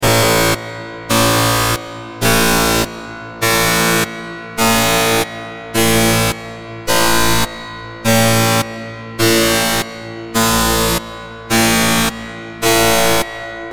دانلود آهنگ هشدار 23 از افکت صوتی اشیاء
جلوه های صوتی
دانلود صدای هشدار 23 از ساعد نیوز با لینک مستقیم و کیفیت بالا